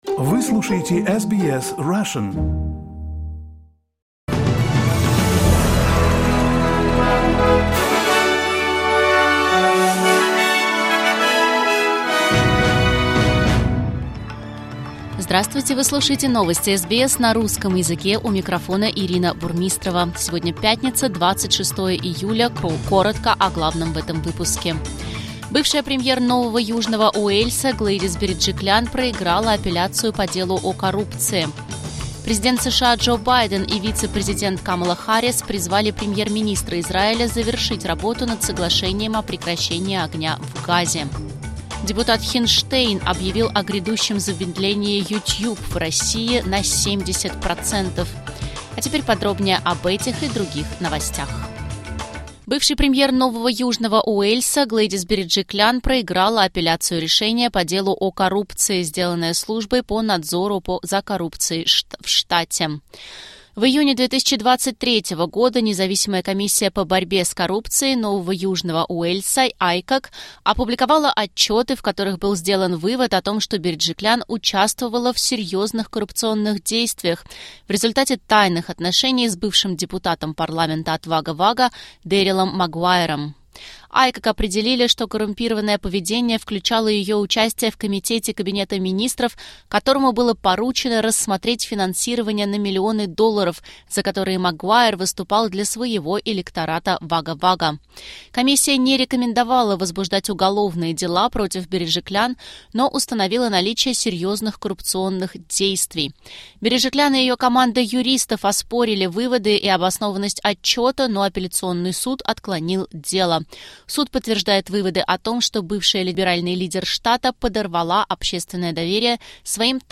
SBS News in Russian — 26.07.2024